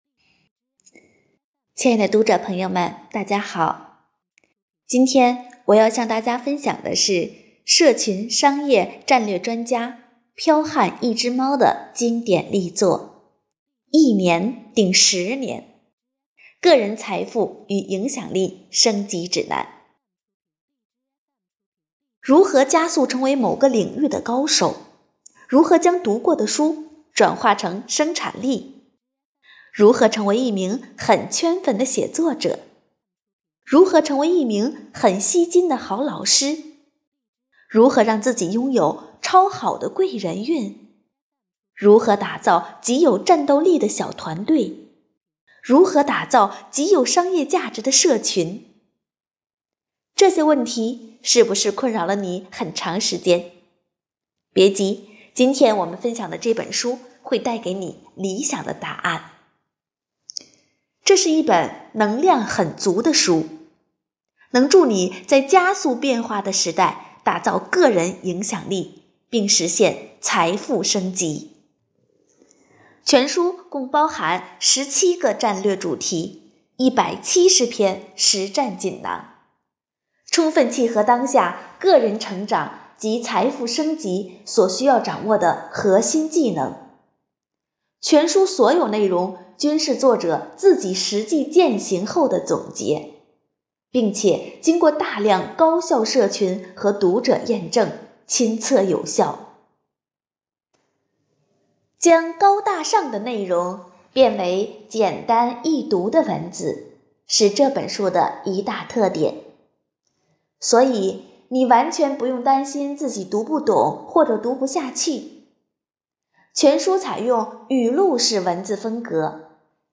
【讲座】丰图讲座 | 一年顶十年——送你一份“个人财富与影响力升级指南”
活动地点：丰南图书馆 线上活动